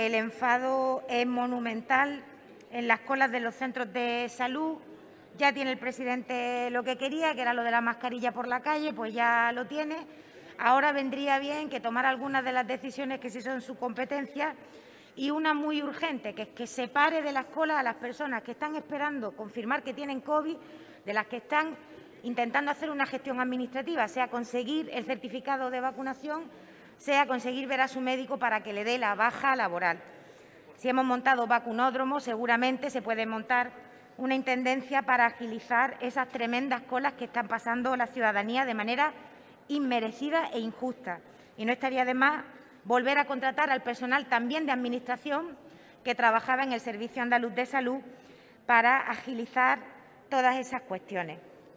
Durante la sesión plenaria, Inmaculada Nieto ha reclamado al Gobierno andaluz que “separe de las colas de los centros de salud a las personas que tienen Covid o están pendientes de hacerse una prueba de aquellas que tienen que hacer gestiones administrativas, ya sea conseguir el certificado de vacunación o conseguir ver a su médico o médica para la baja laboral”.